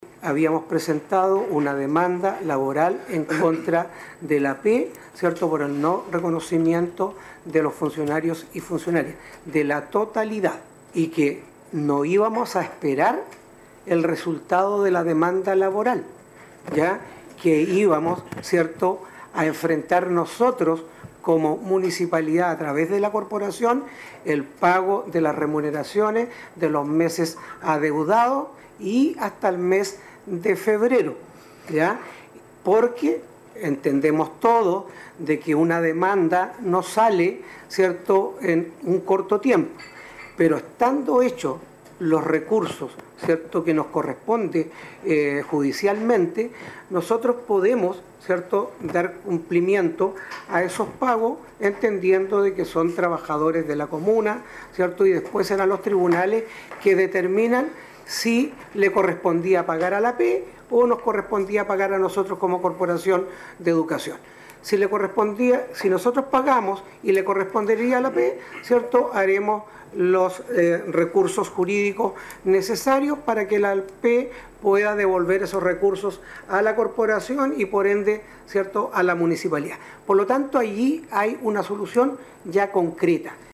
Sobre este preocupante tema, también se refirió el alcalde de la comuna de Ancud, Carlos Gómez, señalando que una de estas vías de solución ya está en marcha mediante demandas en el ámbito laboral en contra del Administrador Provisional.
Todos estos alcances sobre este problema por el que atraviesan los 29 funcionarios no reconocidos por la corporación municipal fueron tratados en la sesión de concejo realizada este lunes en Ancud y que correspondió al tercer llamado para constituir la reunión, luego que en las dos anteriores, no hubo Quorum para iniciarla.